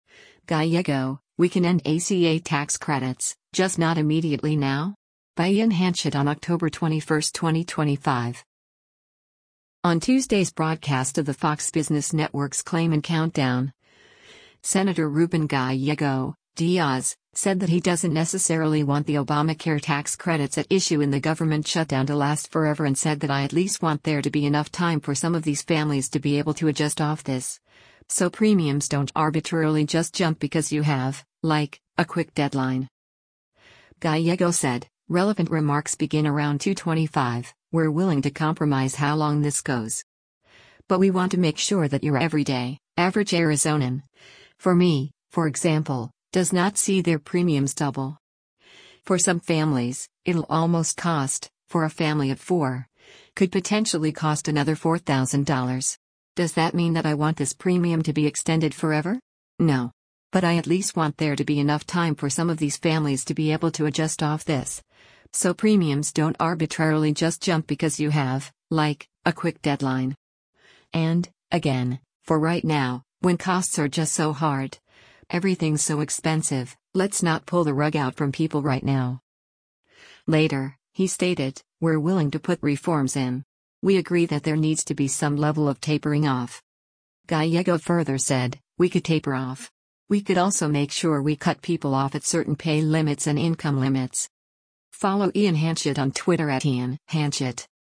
On Tuesday’s broadcast of the Fox Business Network’s “Claman Countdown,” Sen. Ruben Gallego (D-AZ) said that he doesn’t necessarily want the Obamacare tax credits at issue in the government shutdown to last forever and said that “I at least want there to be enough time for some of these families to be able to adjust off this, so premiums don’t arbitrarily just jump because you have, like, a quick deadline.”